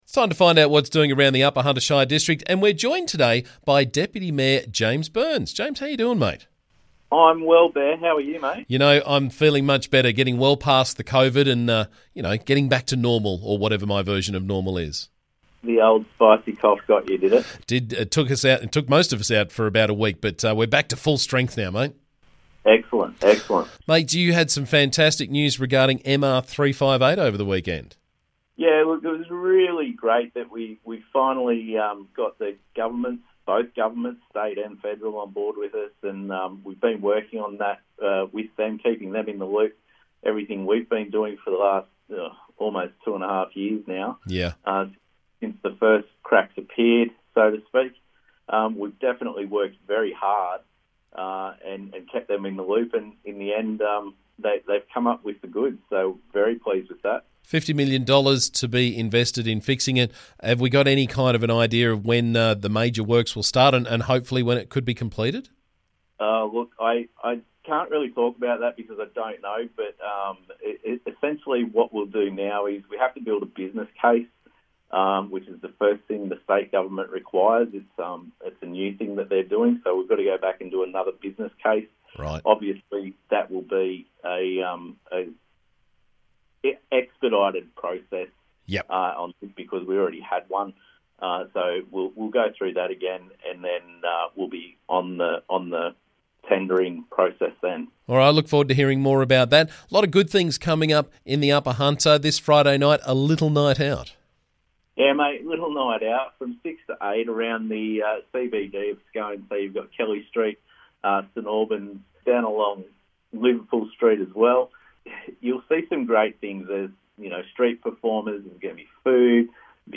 UHSC Deputy Mayor James Burns was on the show this morning to keep us up to date with what's doing around the district.